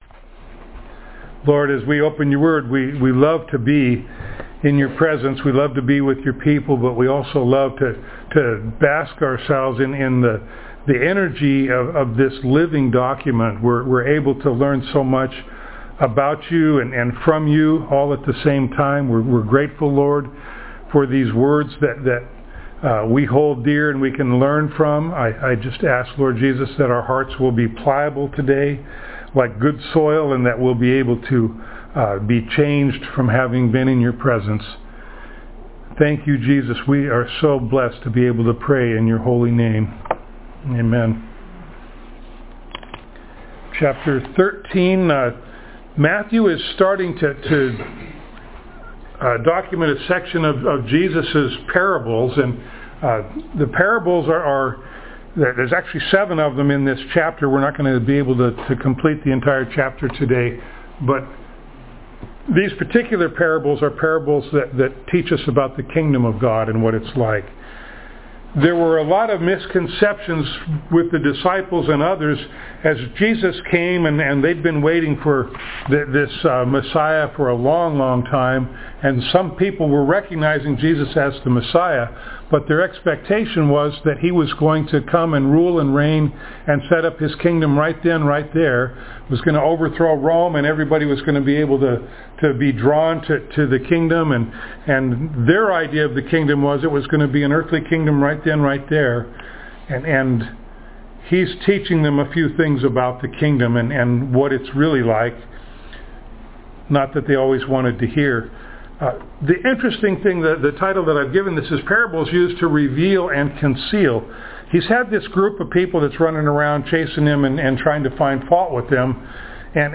Passage: Matthew 13:1-23 Service Type: Sunday Morning